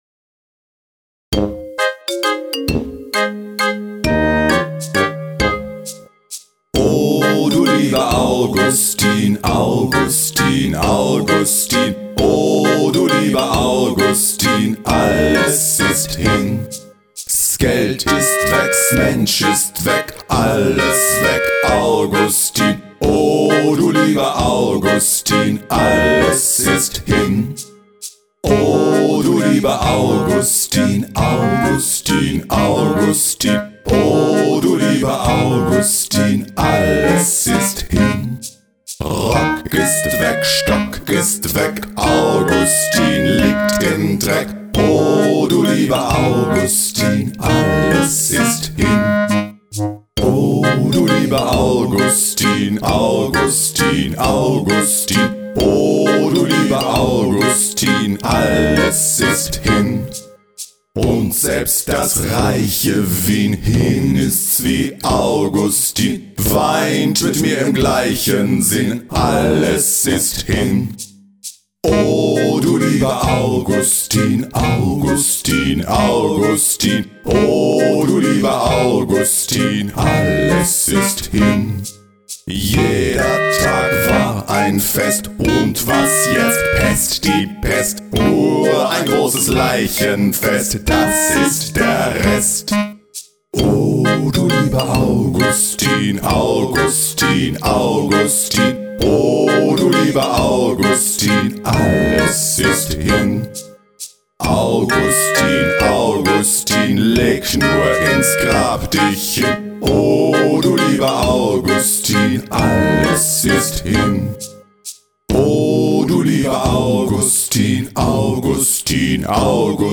Вокальная версия: